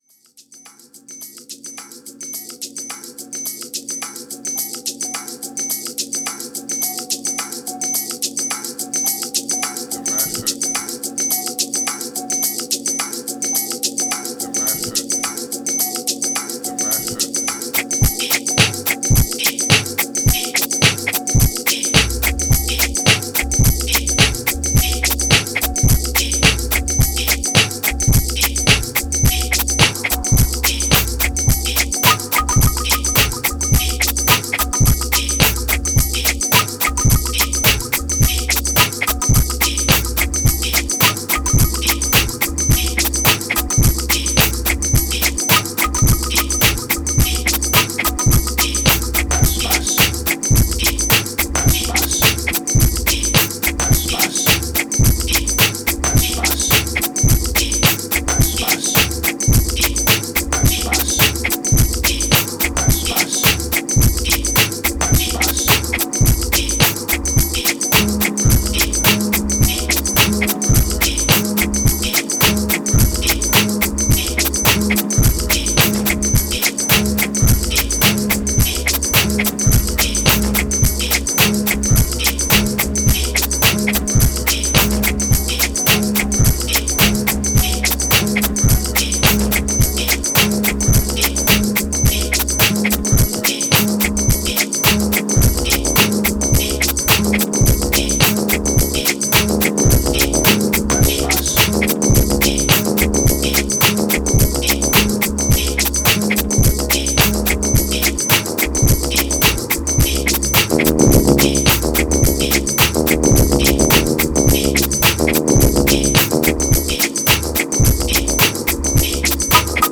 Riddim Action Energy Merger Connect Voices Beat